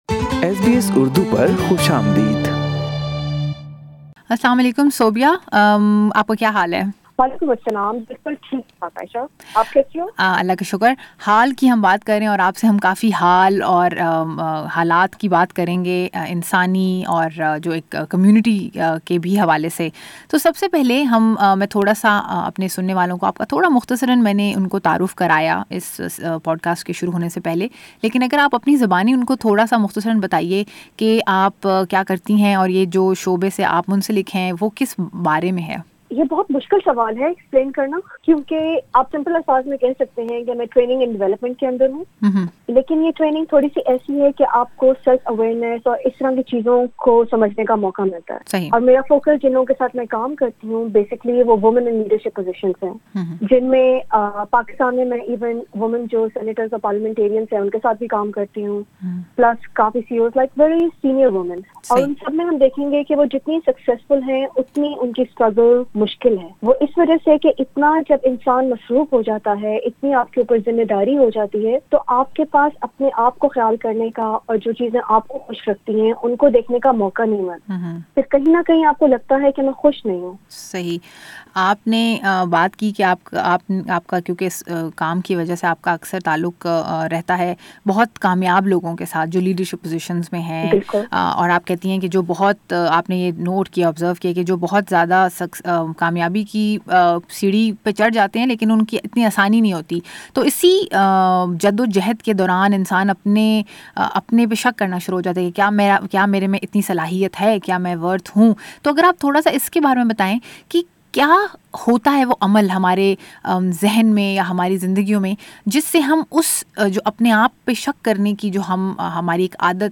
She talks to SBS Urdu on why we doubt ourselves and what can we do to be successful. She suggests that we ask ourselves one basic question: Are we working towards our goals or someone else's goals?